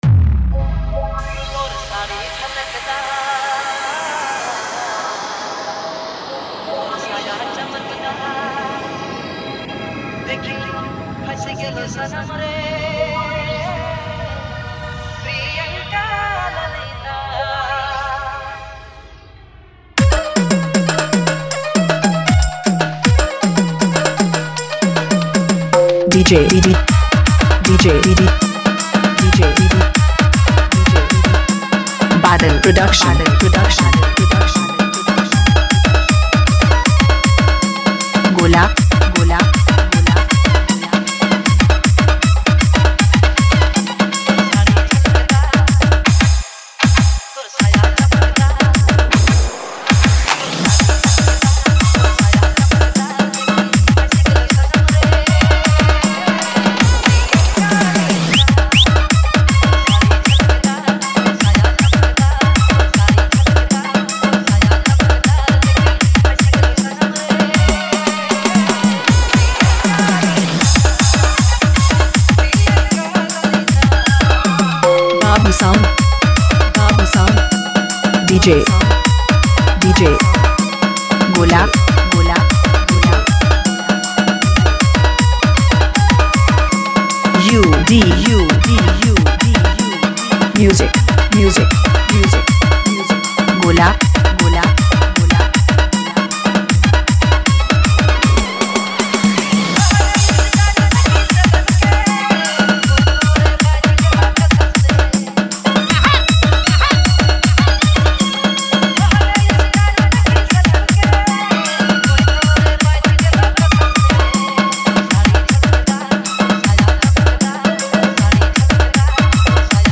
is a lively and energetic Nagpuri DJ remix track